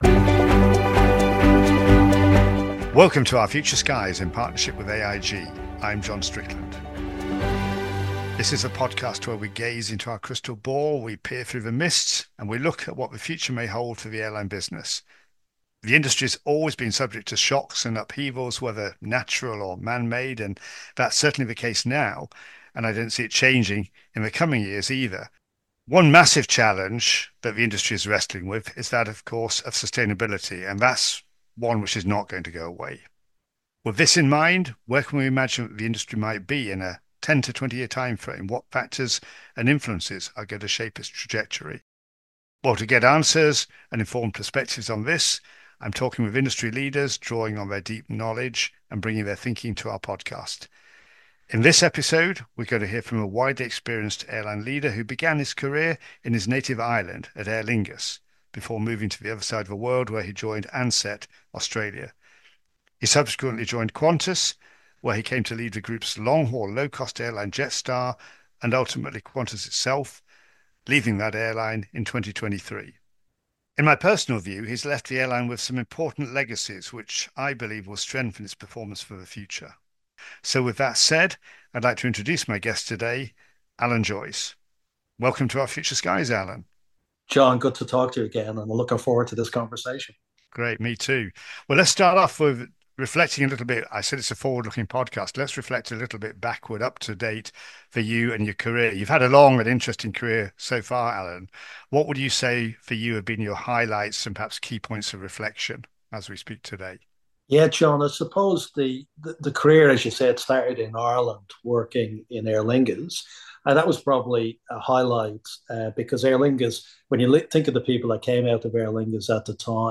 In conversation with Alan Joyce